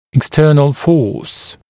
[ɪk’stɜːnl fɔːs] [ek-] [ик’стё:нл фо:с] [эк-] внешняя сила